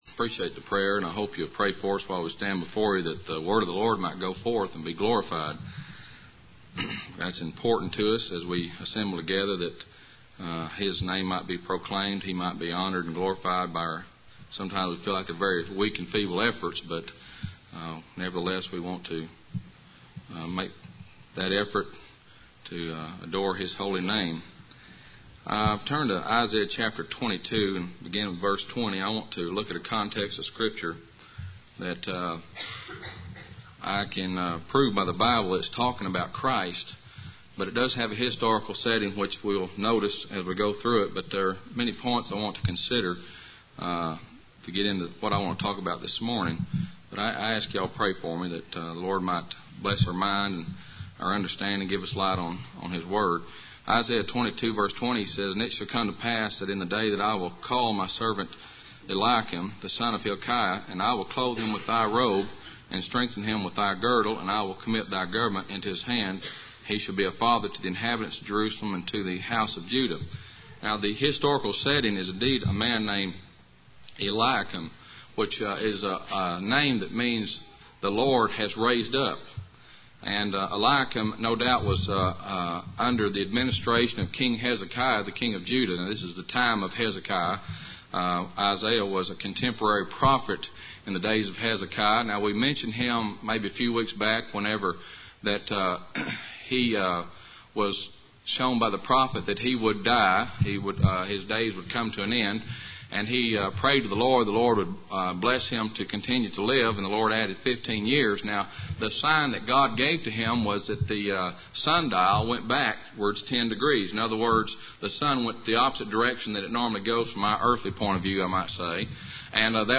Passage: Isaiah 22:20-25 Service Type: Middleton Creek PBC (MS) %todo_render% « Depravity